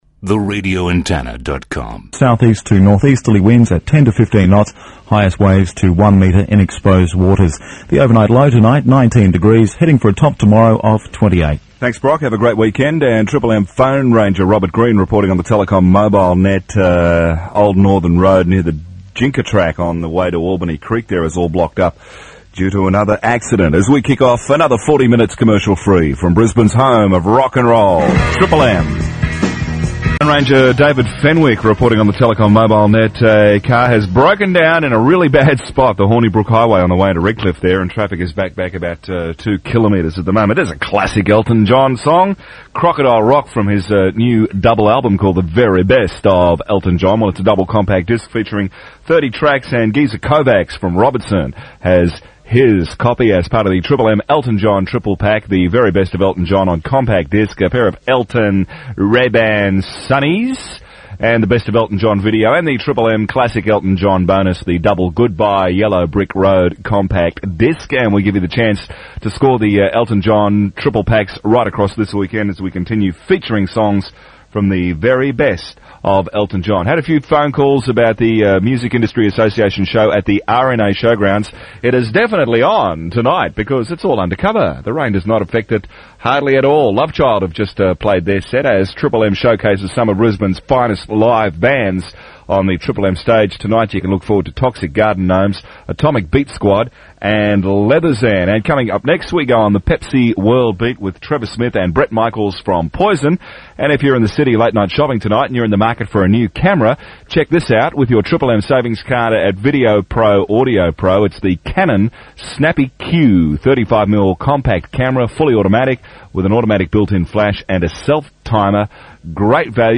Love it when you find something you knew you had, this was transferred from a cassette about 5 years ago, from memory this timeframe was not long after new management were in control, (Hoyts Media) also when the front wall came to life see pic below